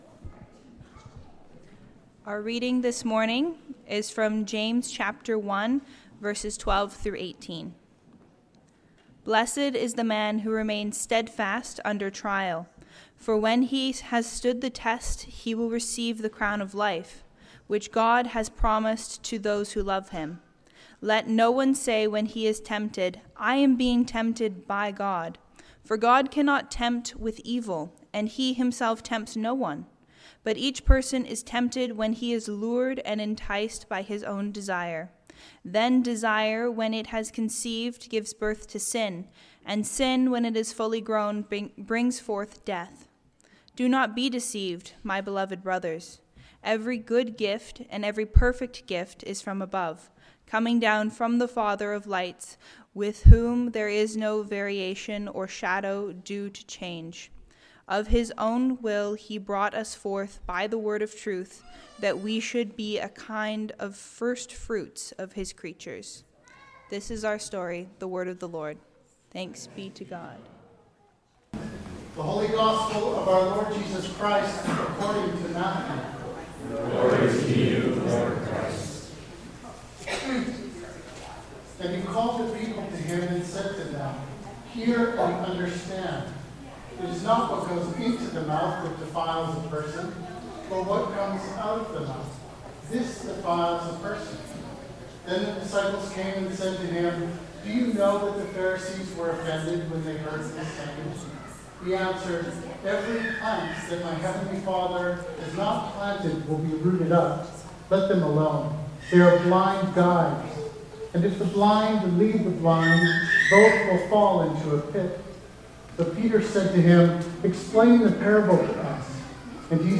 Weekly sermons from Christ's Church Vancouver Island Canada.